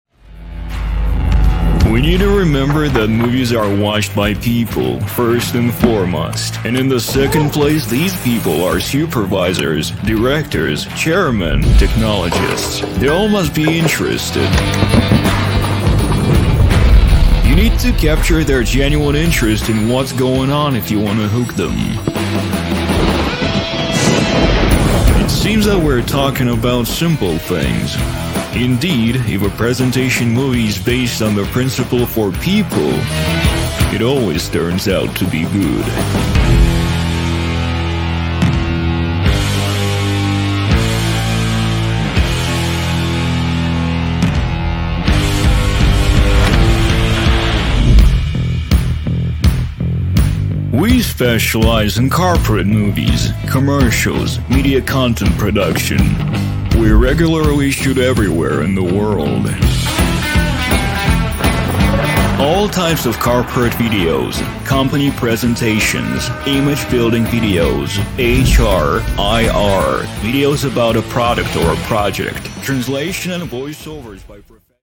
Business Commercial
Муж, Рекламный ролик/Зрелый
Спокойный, глубокий бас-баритон. Озвучиваю на английском без акцента! Студийное качество записи.